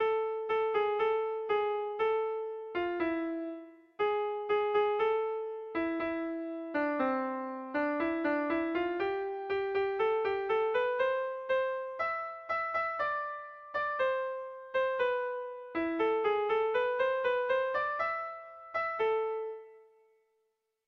Irrizkoa
Sei puntuko berezia
ABDEFG